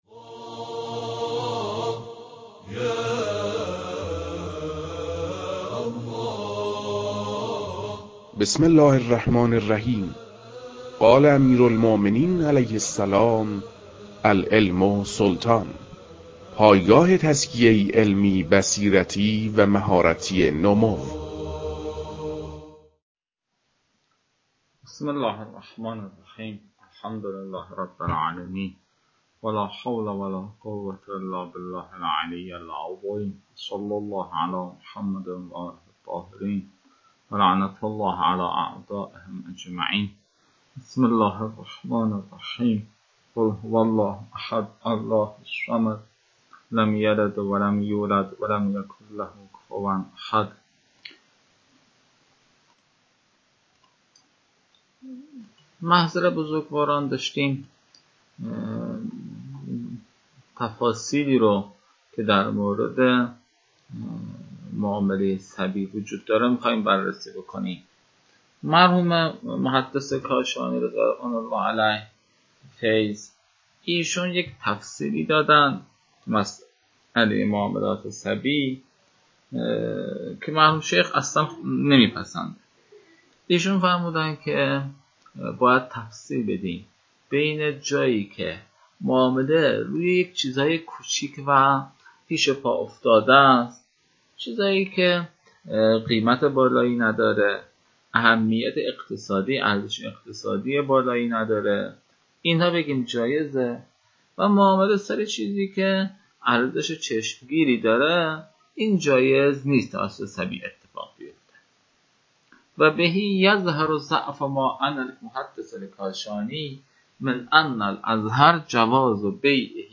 فایل های مربوط به تدریس مباحث تنبیهات معاطات از كتاب المكاسب متعلق به شیخ اعظم انصاری رحمه الله